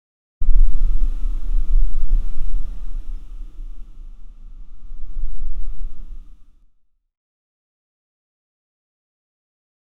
Game sound effect for a match-3 action. Soft mechanical click like fiery particles being contained. Smooth and light, industrial but polished, with a subtle engine purr and a soft steam release.